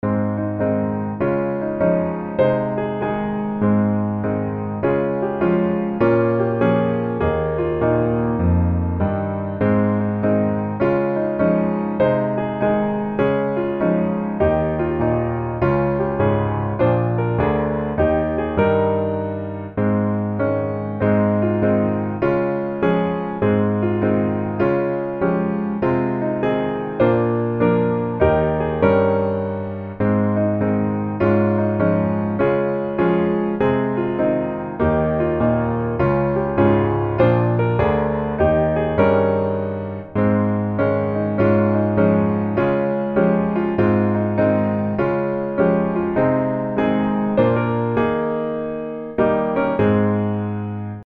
As Dur